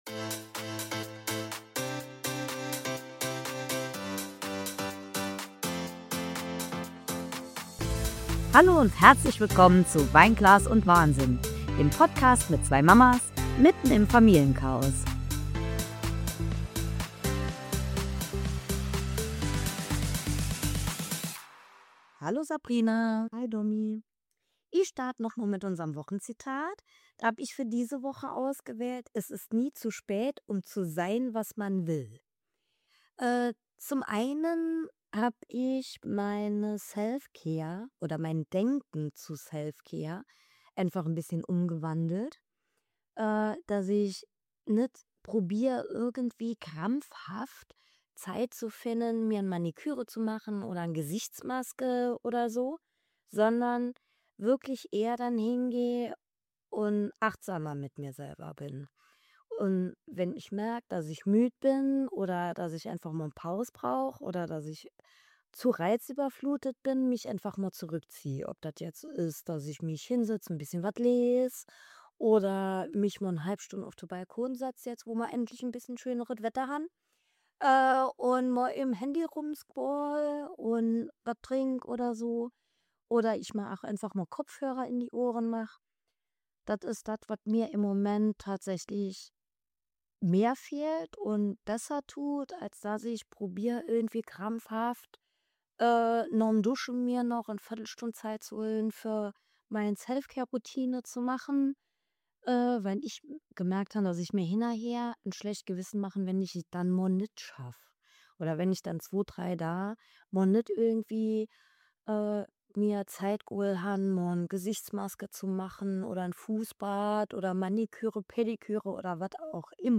In dieser herrlich chaotischen Frühlingsfolge nehmen wir euch mit zwischen Vogelgezwitscher, Kaffeeduft und latentem Familienwahnsinn.